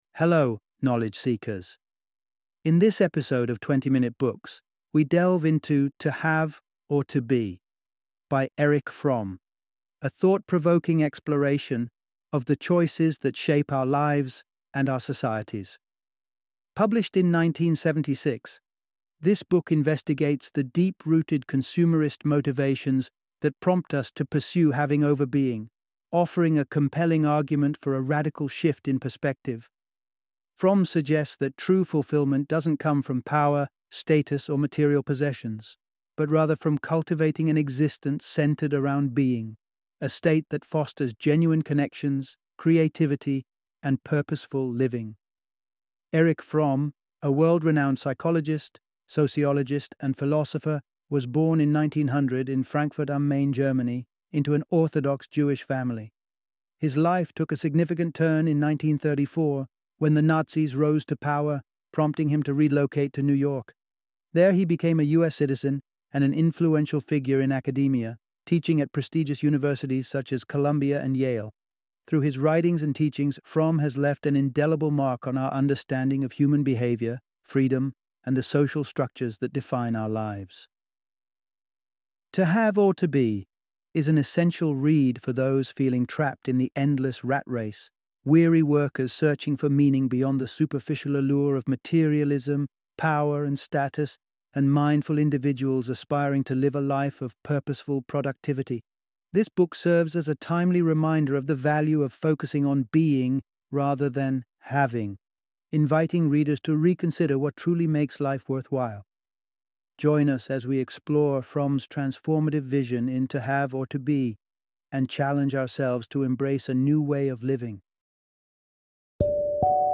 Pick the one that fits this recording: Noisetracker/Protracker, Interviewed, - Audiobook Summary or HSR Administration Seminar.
- Audiobook Summary